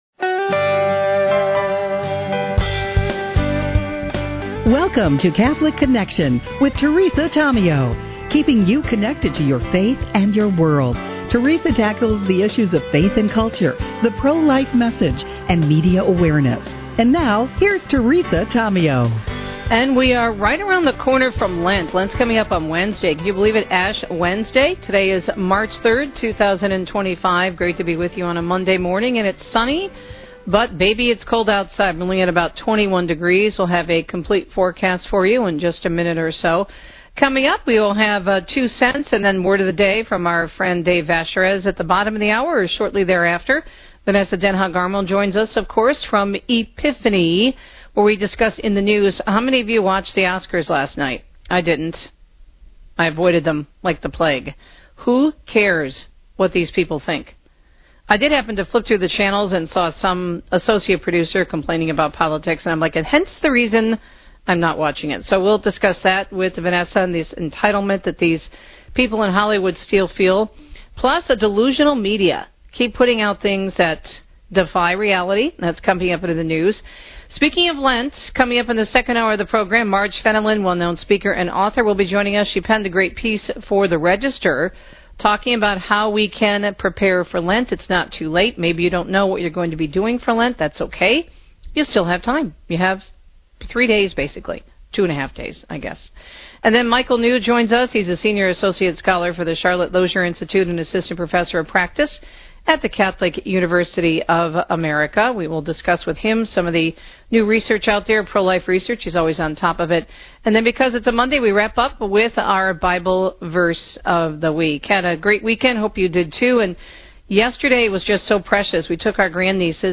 She discusses social issues, media awareness, and interviews local businesses, youth, families, and community leaders in Southeastern Michigan.